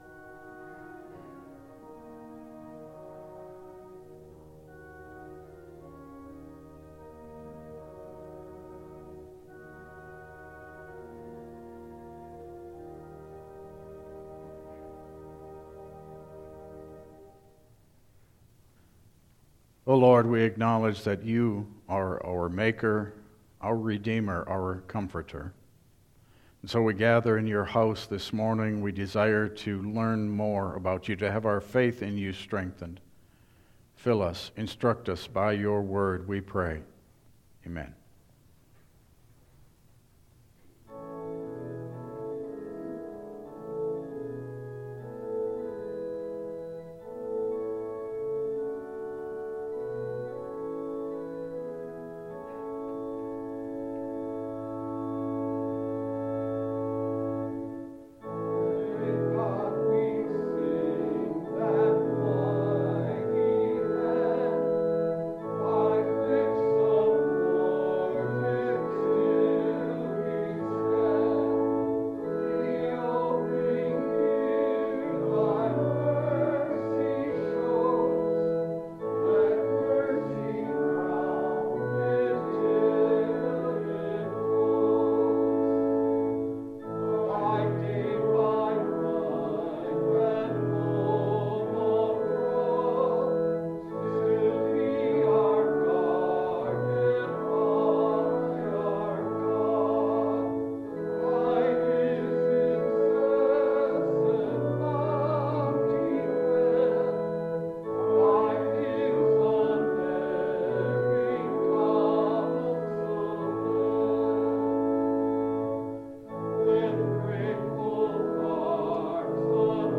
Passage: Matthew 2:1-12 Service Type: Regular Service